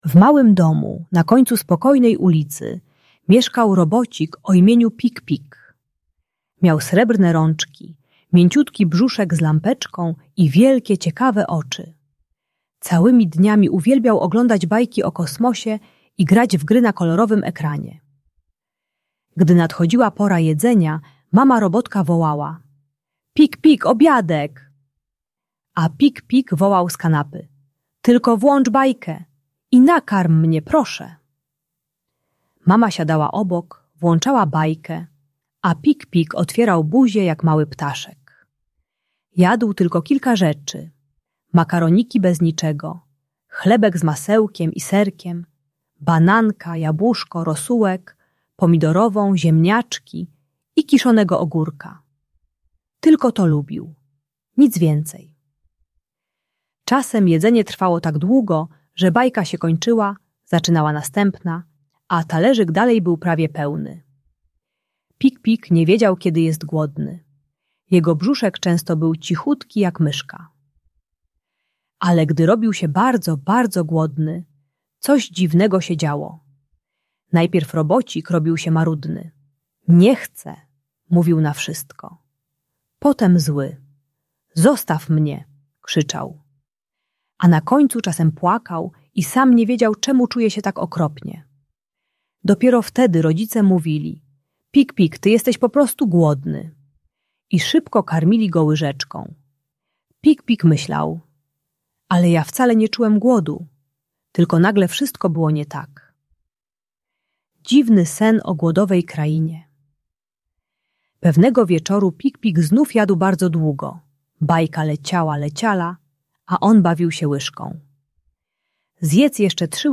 Historia Pik-Pika: Przyjaciel Swojego Brzuszka - Problemy z jedzeniem | Audiobajka